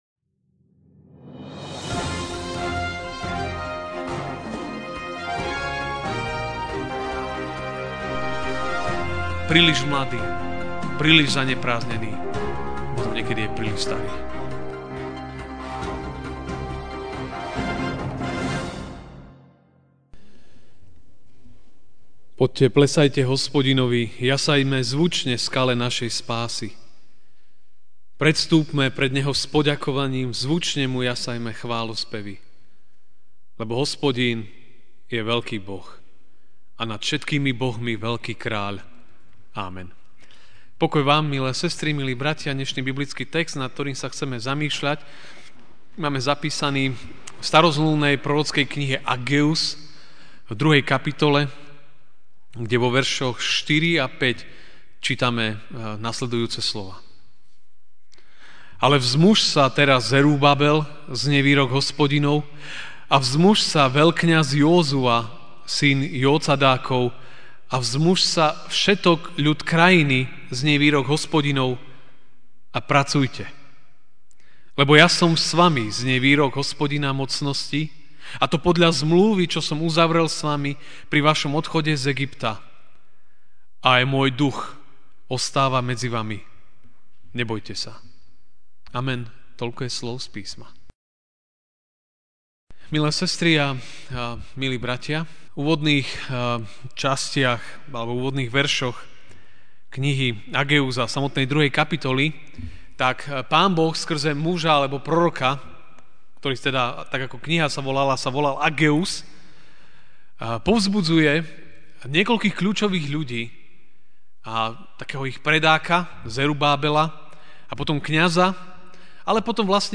- Evanjelický a.v. cirkevný zbor v Žiline